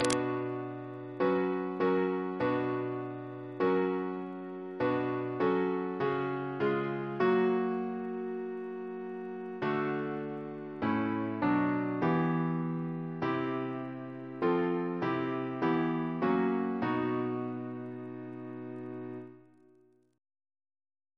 Double chant in B minor Composer